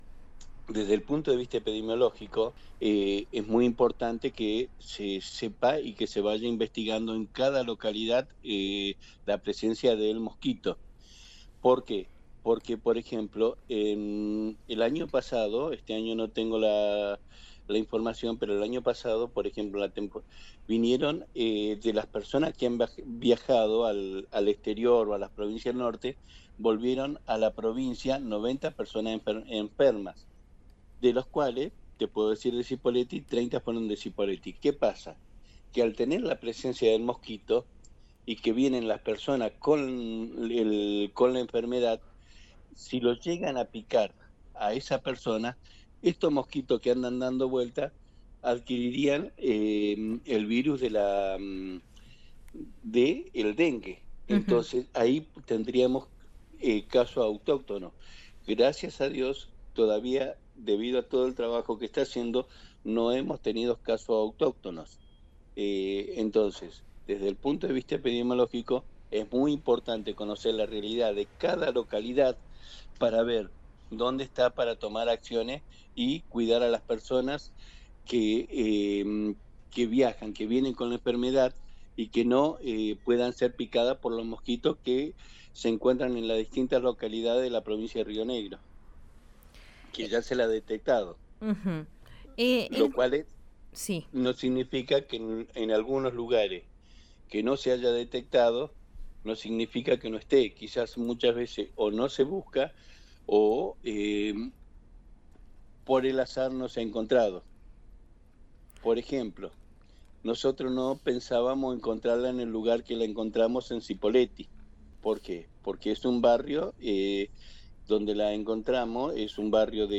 en el aire de RÍO NEGRO RADIO